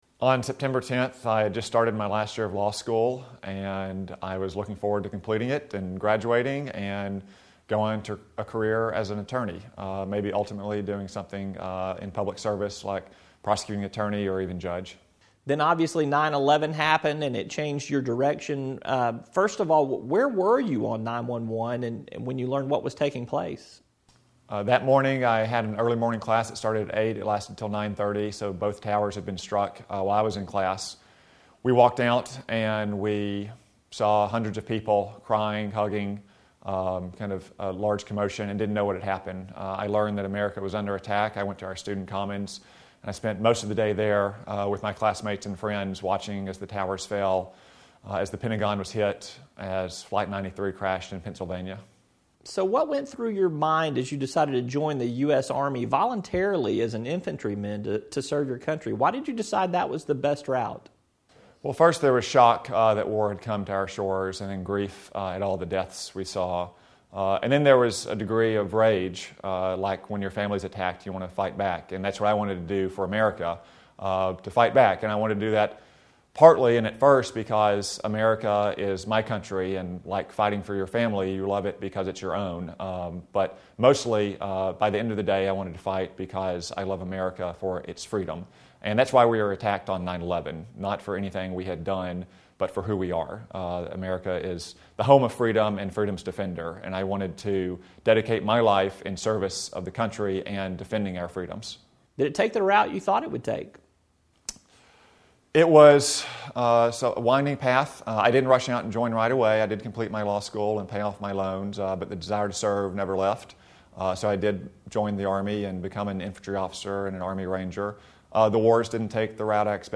A Conversation with Tom Cotton